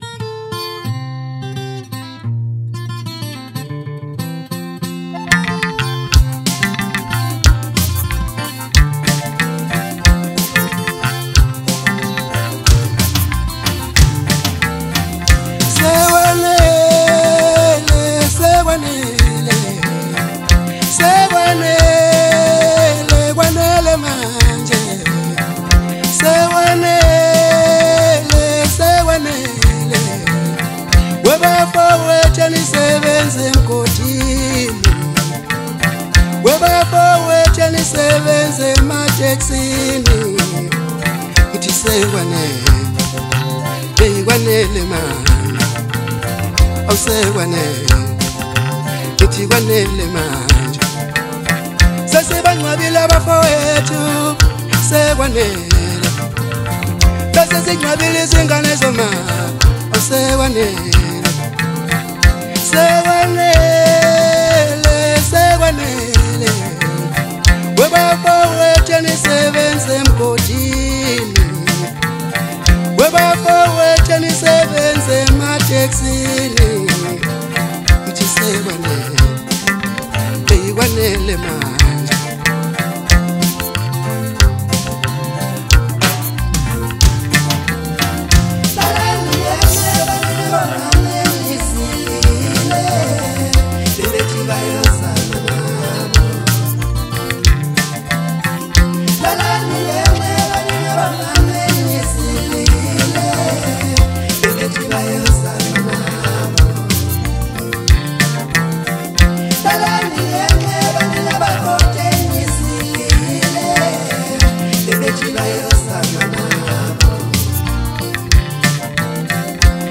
House Songs